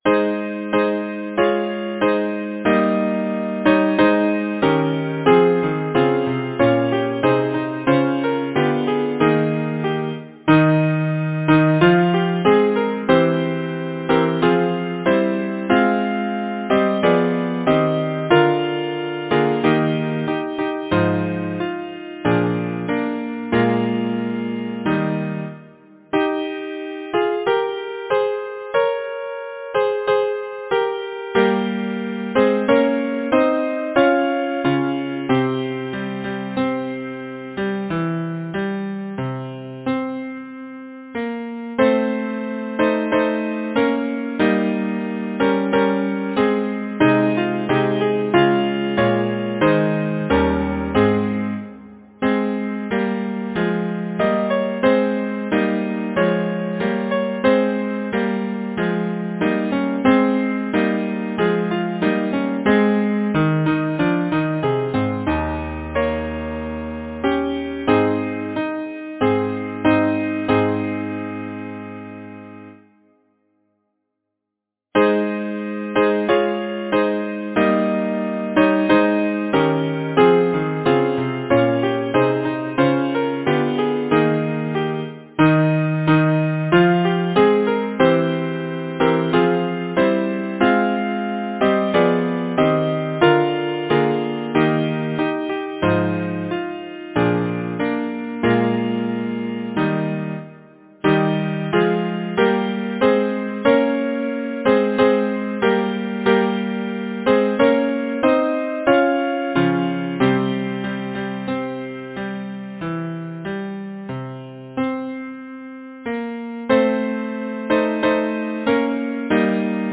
Title: The Ladder Composer: John Liptrot Hatton Lyricist: Beatrice Abercrombie Number of voices: 4vv Voicing: SATB Genre: Sacred, Partsong
Language: English Instruments: A cappella